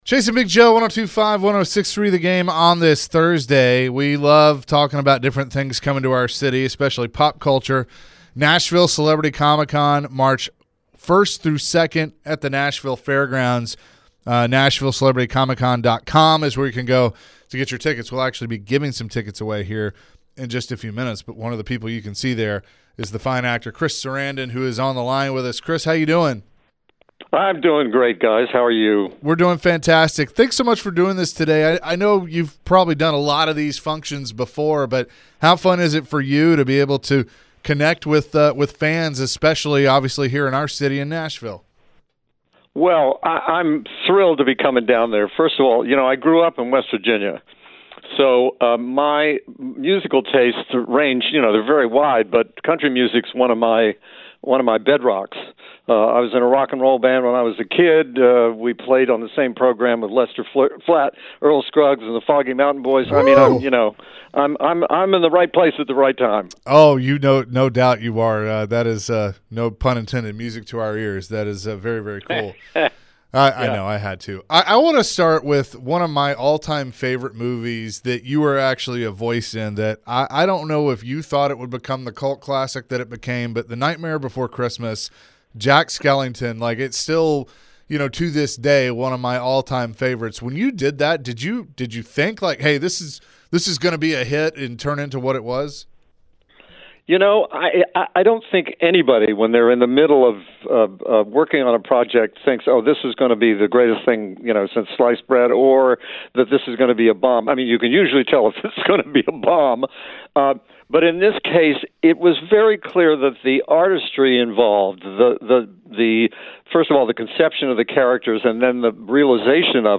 American actor Chris Sarandon joined the show as he will be at the Nashville Comic Con. Chris shared some of his favorite movies he was apart of.